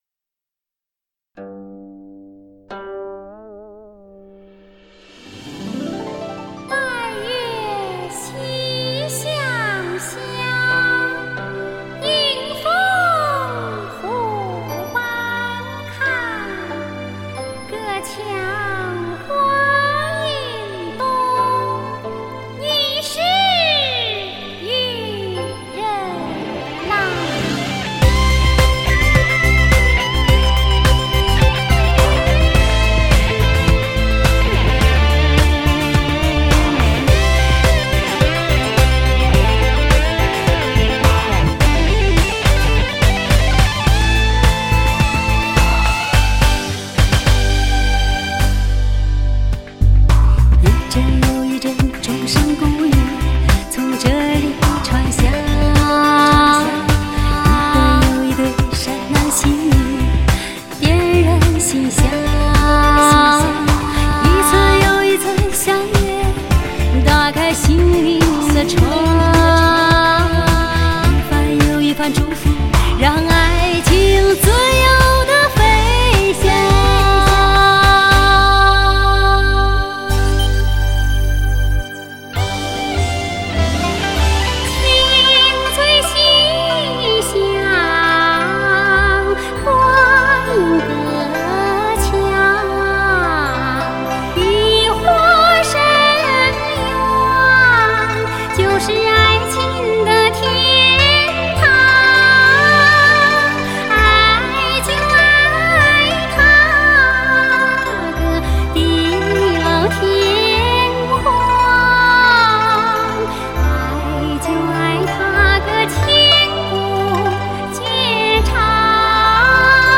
将流行与戏剧完美结合的中国第一跨界女伶